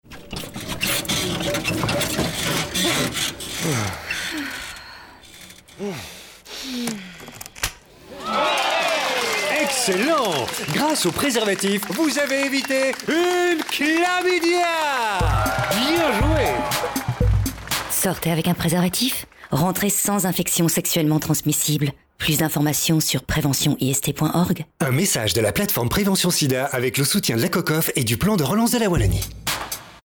Spot radio "Excellent"